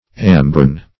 Search Result for " ambrein" : The Collaborative International Dictionary of English v.0.48: Ambrein \Am"bre*in\, n. [Cf. F. ambr['e]ine.